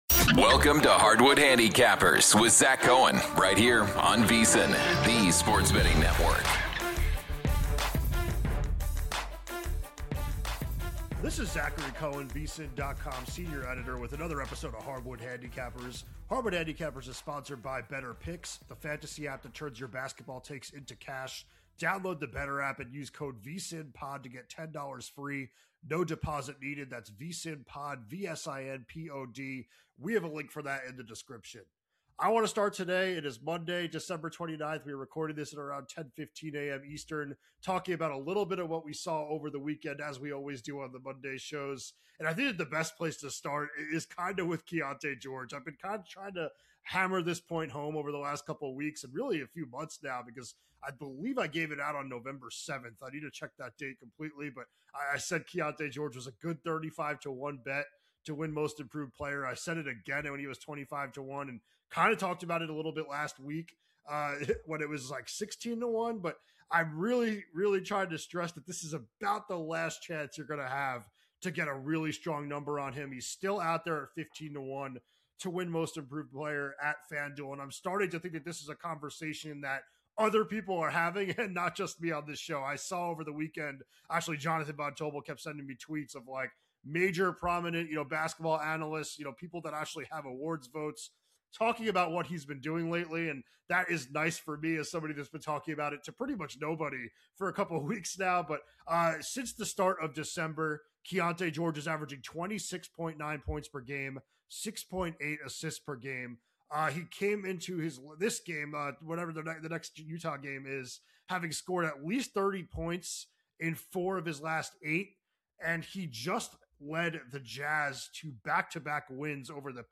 1 The Evolution of Basketball Analytics | INTERVIEW w